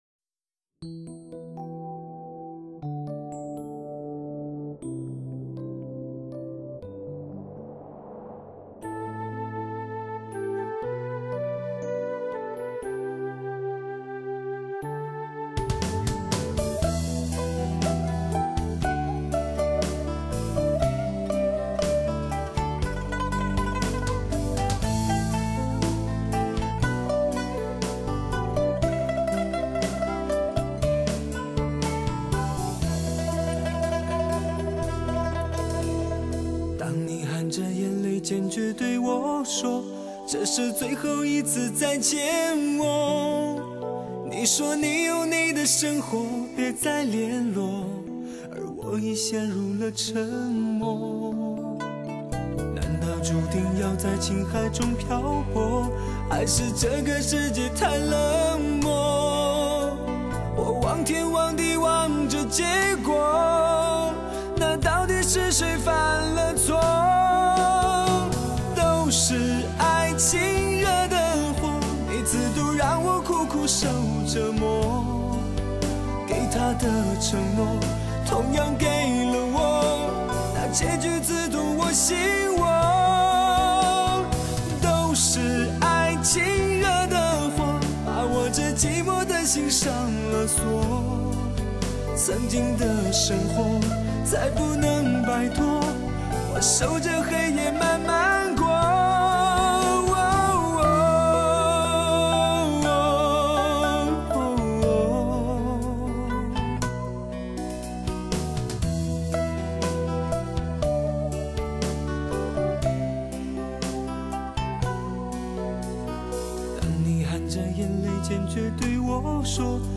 Magix Virtual Live高临场感CD。